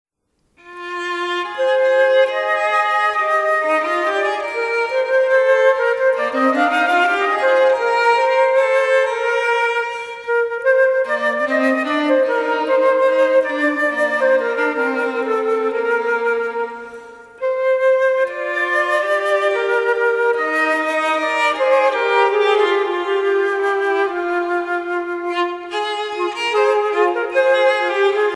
Noël, Nativité
Chants traditionnels
Pièce musicale éditée